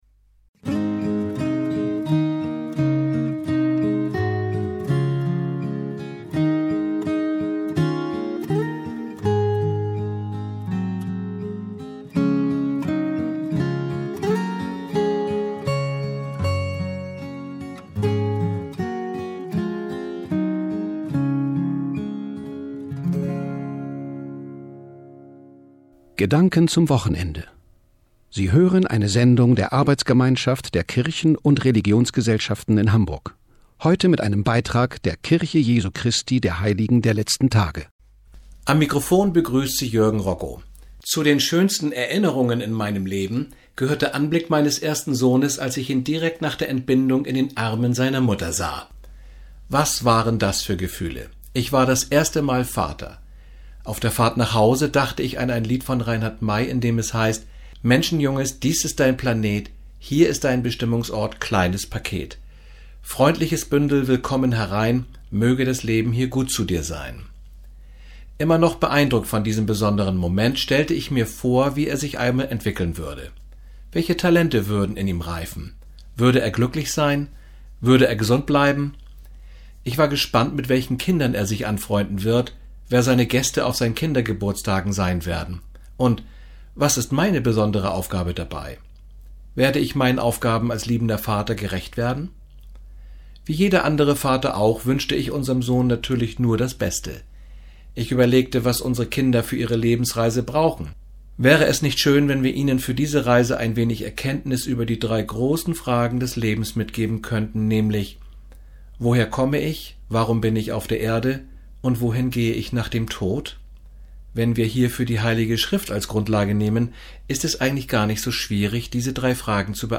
Die am Samstag, den 8. Februar 2014, auf dem Hamburger Bürger- und Ausbildungskanal TIDE gesendete Botschaft der Kirche Jesu Christi der Heiligen der Letzten Tage mit dem Titel "Orientierung im Leben" steht ab sofort als Podcast auf der Presseseite zur Verfügung.